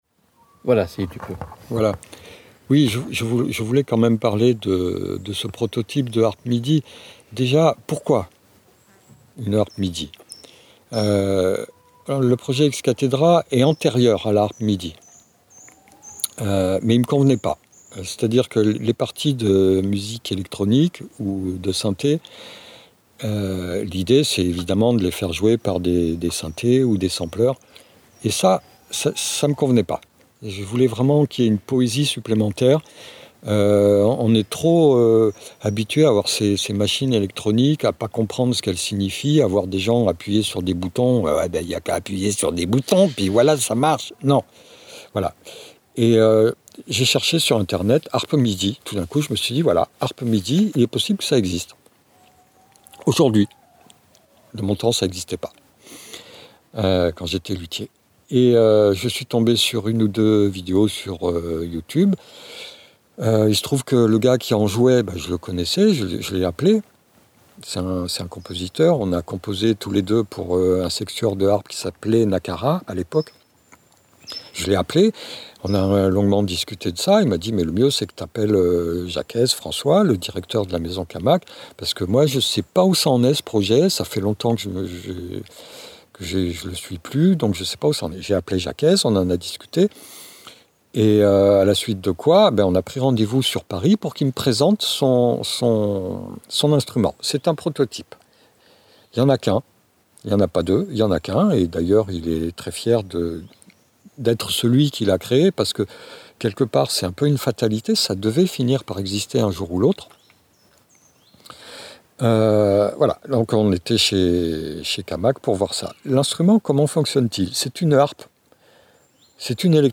La harpe MIDI / OSC
Cet unique prototype de harpe électronique MIDI et OSC est une grande harpe de concert.
presentation_harpe_midi.mp3